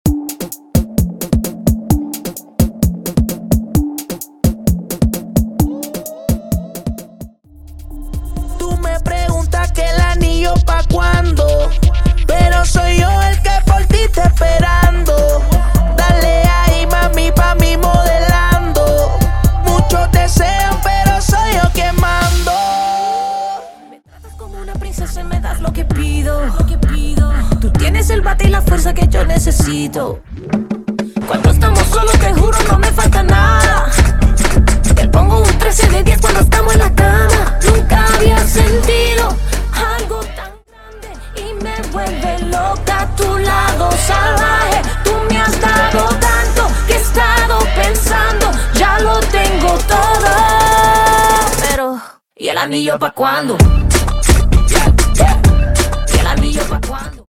Latin genres